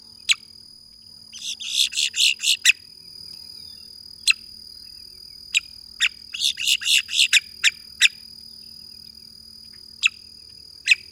Boat-tailed Grackle
Quiscalus major